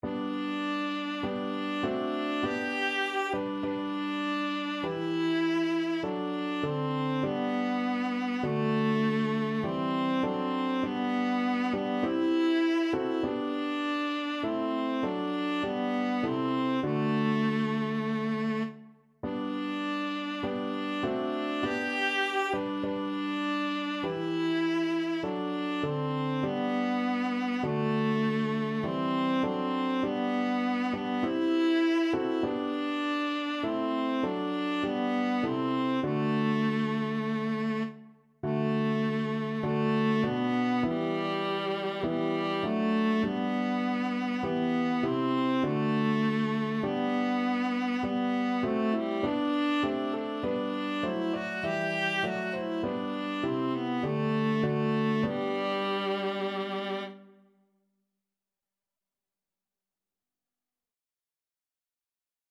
Christmas Camille Saint-Saëns Praise Ye the Lord of Hosts (Tollite Hostias) from Christmas Oratorio op.12 Viola version
Viola
G major (Sounding Pitch) (View more G major Music for Viola )
Maestoso